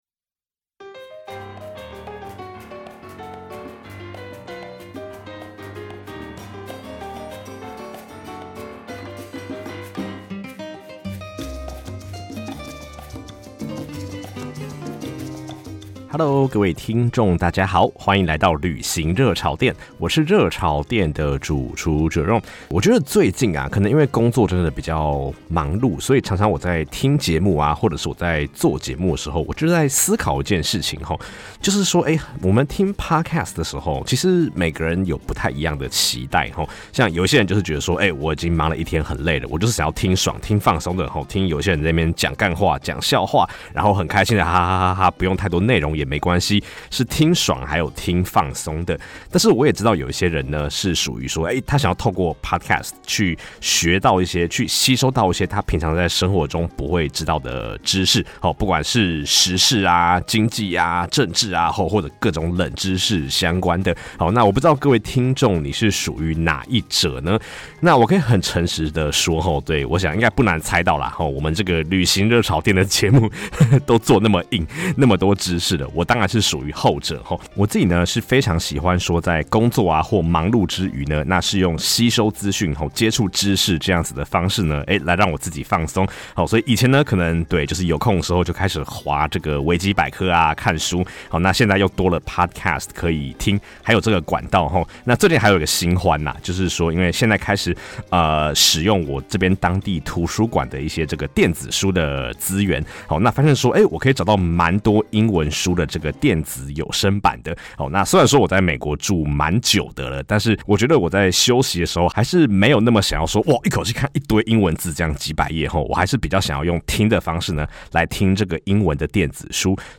基層社畜一枚，用下班後的剩餘精力旅行與做節目，大多數的單集都是自己講的。